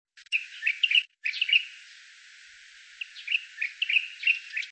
12-1墾丁烏頭翁1.mp3
烏頭翁 Pycnonotus taivanus
屏東縣 恆春鎮 墾丁青年活動中心
海岸林
鳴唱
Denon Portable IC Recorder 型號 DN-F20R 收音: 廠牌 Sennheiser 型號 ME 67